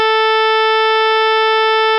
Here is an example of two sounds, produced using a computer program, which use the exact same sinusoidal functions with the exact same amplitudes, but some of them have had a phase shift applied (e.g. were delayed or advanced in time compared to the other sinusoidal functions).
As long as you keep the volume low enough so that non-linear effects (in the electronics or in your hearing) are not important, most hear them as not just similar, but identical.